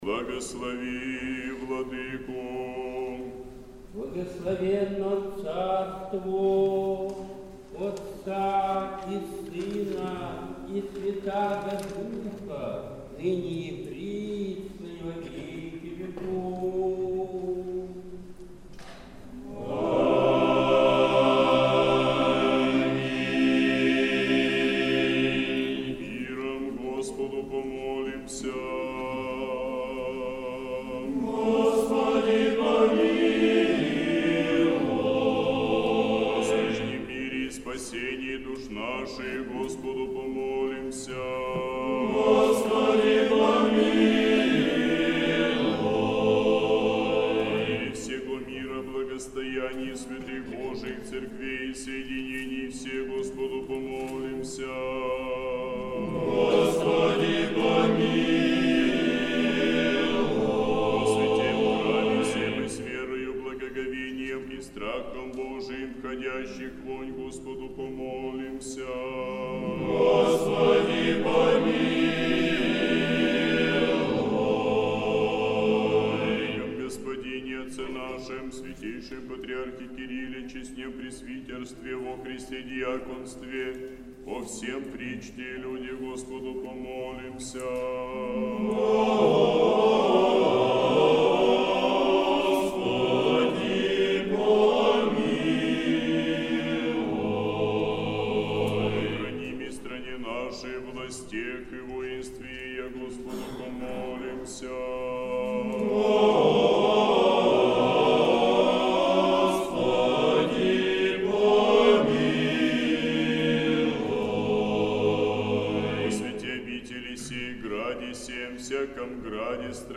Божественная литургия в Сретенском монастыре в день Собора Архистратига Михаила и прочих Небесных Сил бесплотных
Сретенский монастырь. Божественная литургия. Хор Сретенской Духовной Семинарии.